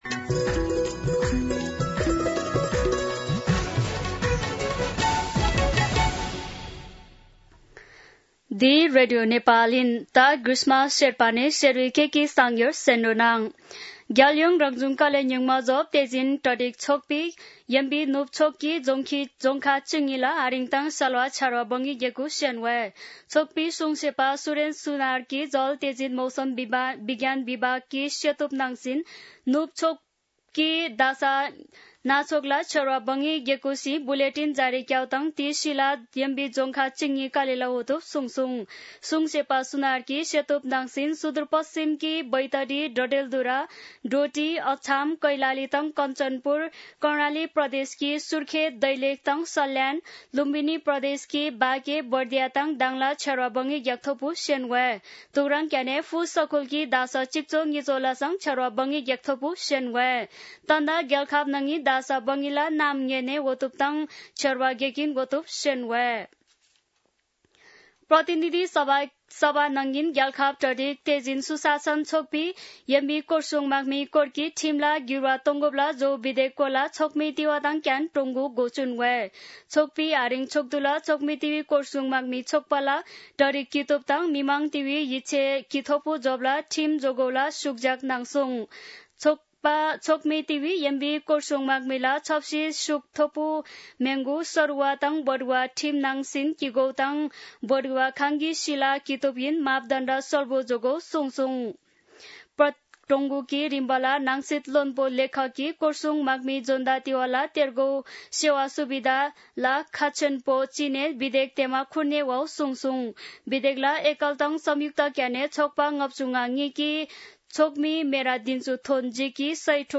An online outlet of Nepal's national radio broadcaster
शेर्पा भाषाको समाचार : १५ असार , २०८२
Sherpa-News-3-15.mp3